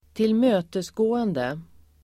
Uttal: [²tilm'ö:tesgå:ende]